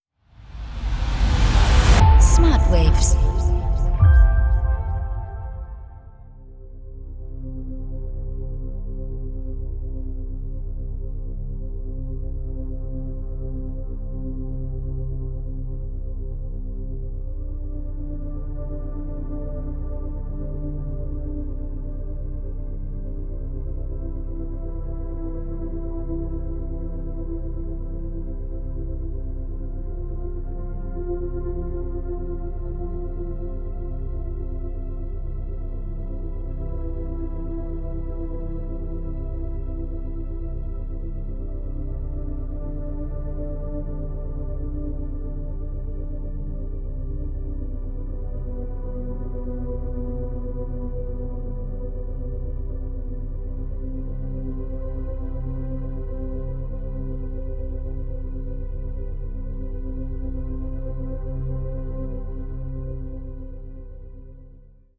• Methode: Binaurale Beats
• Frequenz: 7 Hertz